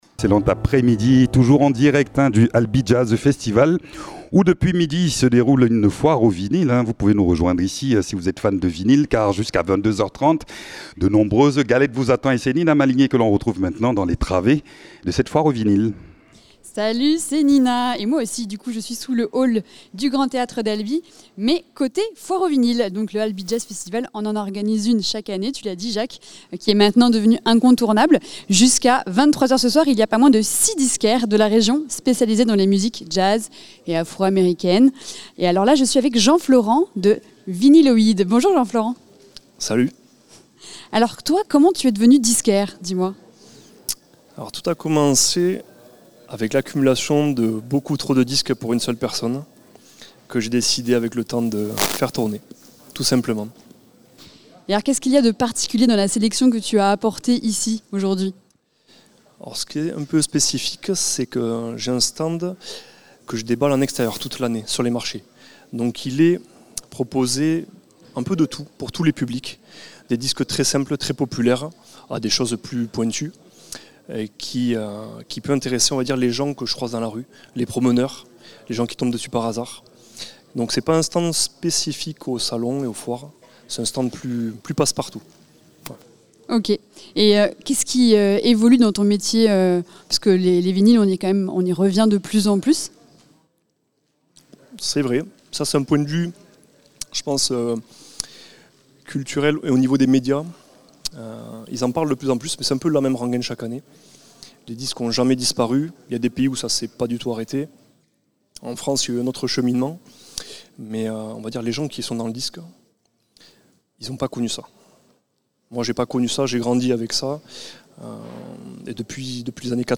Une balade dans la foire aux vinyles du Albi Jazz Festival